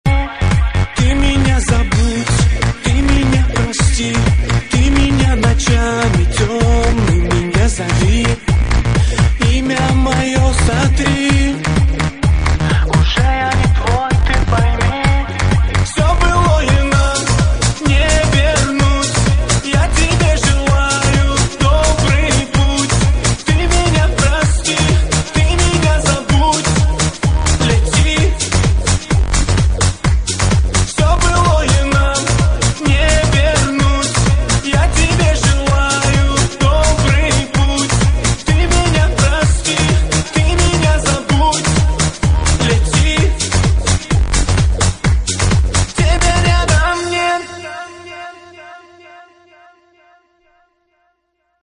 • Качество: 128, Stereo
грустные
dance
Electronic
club
клубнячок